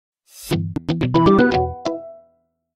Virgule sonore - Audio logo